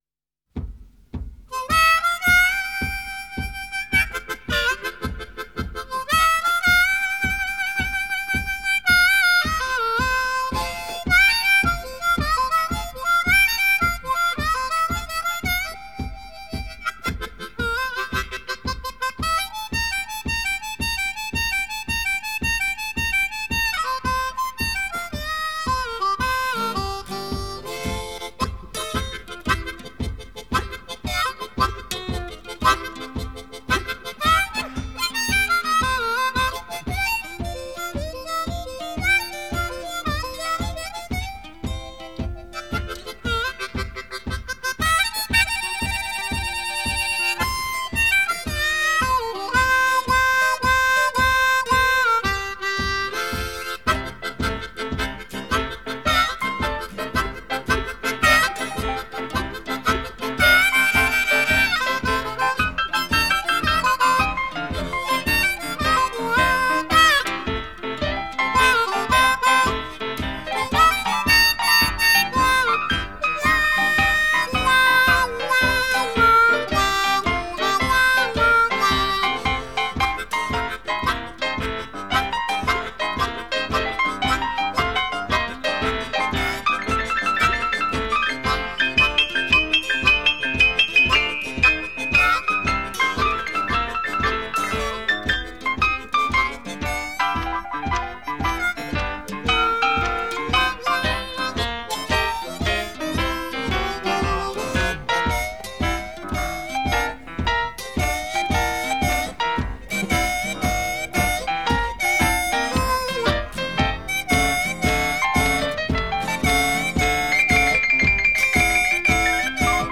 by Jean-Jacques Milteau
C调曲目，使用F调口琴二把位演奏